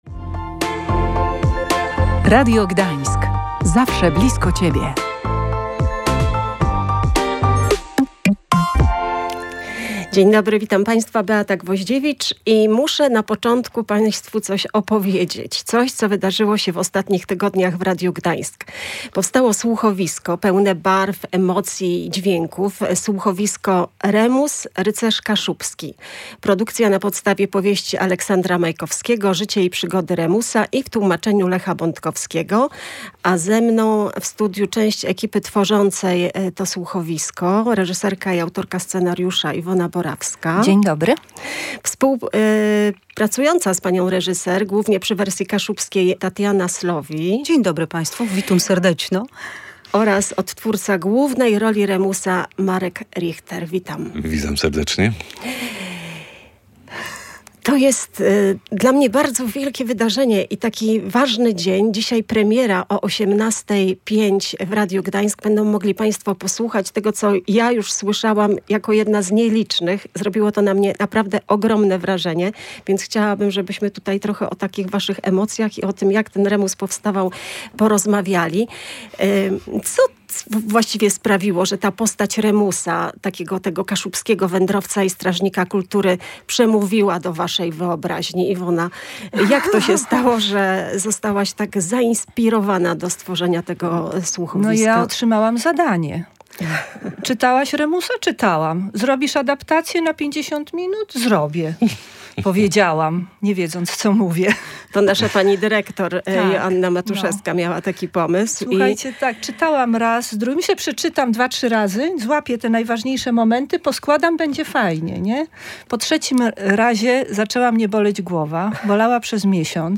W naszym studiu gościliśmy część ekipy, która je stworzyła.